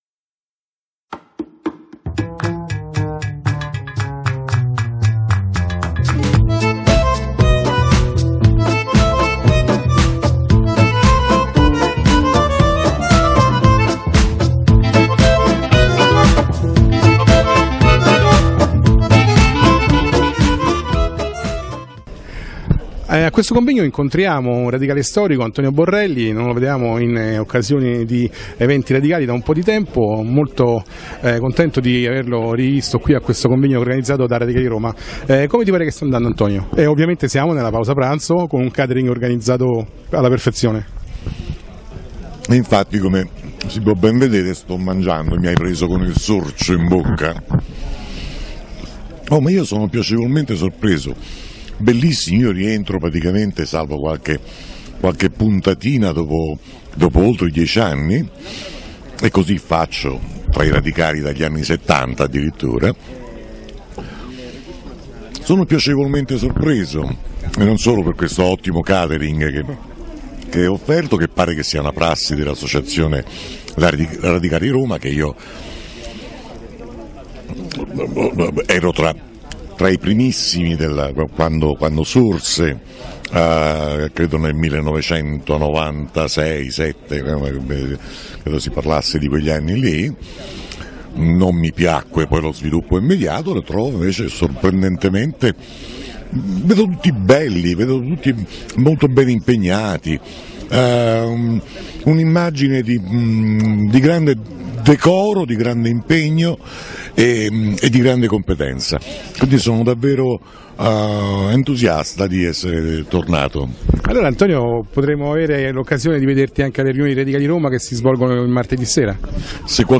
Convegno "ROMA CHIAMA EUROPA" organizzato dall'associazione Radicali Roma, 22 marzo 2014 Domus Talenti, Roma.